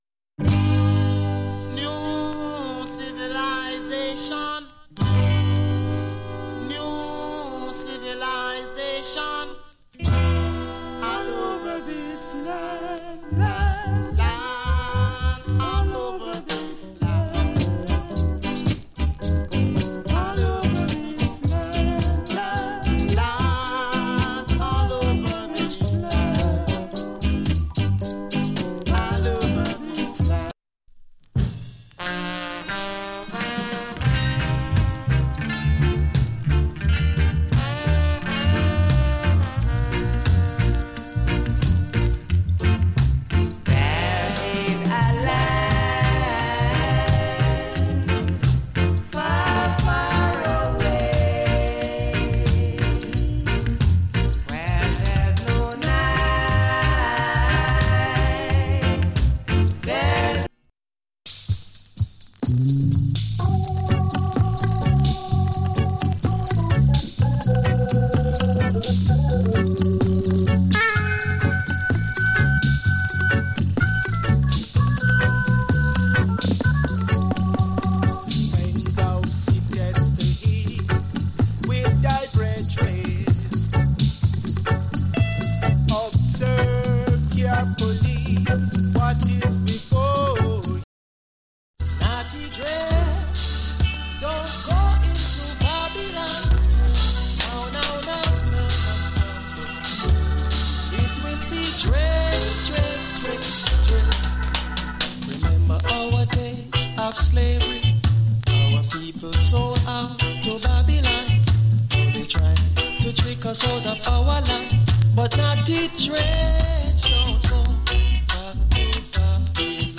チリ、パチノイズわずかに有り。
TOP ROOTS ROCK